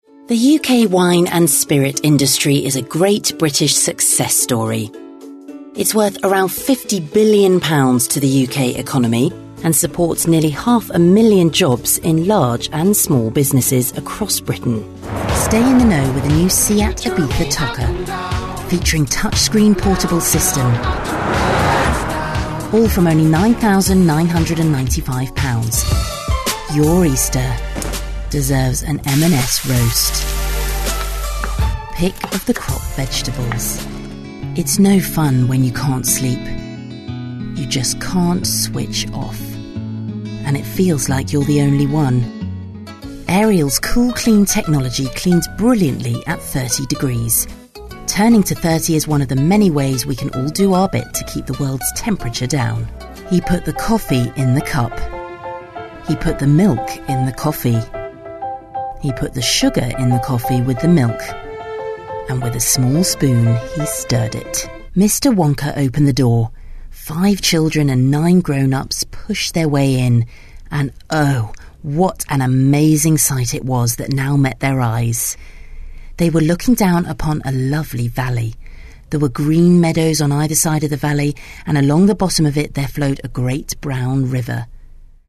Female / 30s, 40s / English / RP, Southern
Showreel